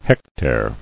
[hec·tare]